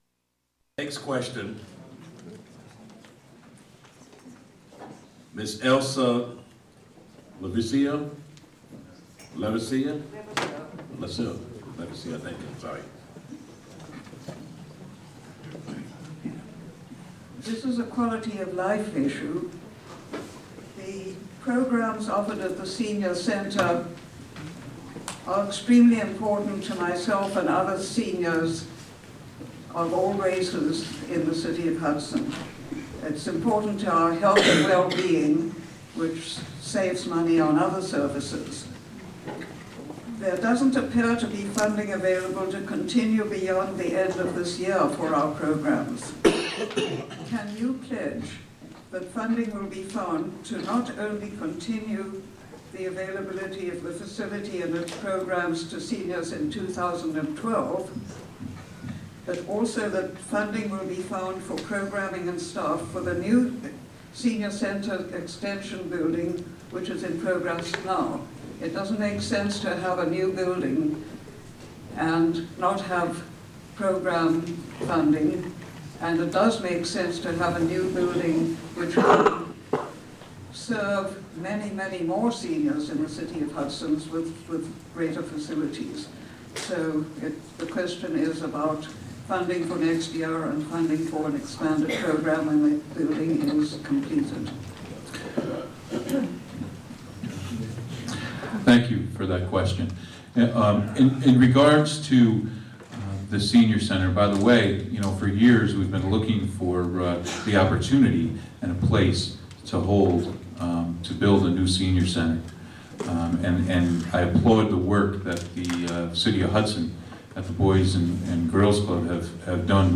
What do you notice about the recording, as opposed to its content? HAALA Hudson Mayoral Candidate Forum: Oct 25, 2011: 6pm - 8pm At the JLE School in Hudson. Radio for Open Ears HAALA candidate forum broadcast live on WGXC.